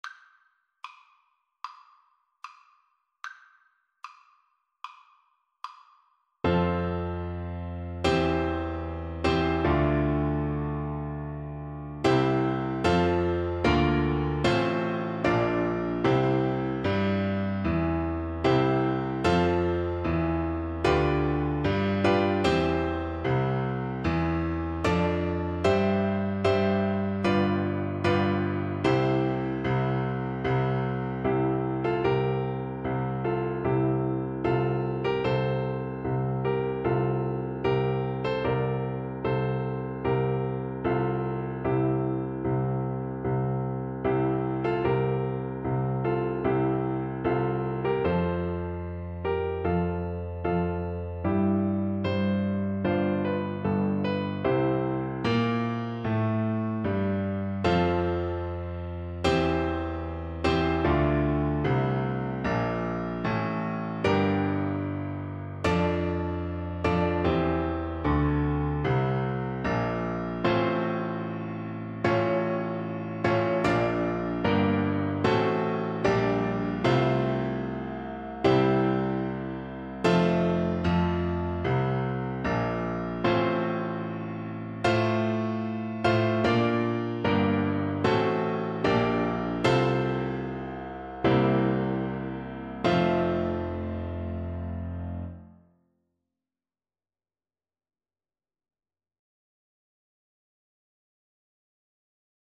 Free Sheet music for Soprano (Descant) Recorder
Recorder
4/4 (View more 4/4 Music)
F major (Sounding Pitch) (View more F major Music for Recorder )
Maestoso risoluto
Traditional (View more Traditional Recorder Music)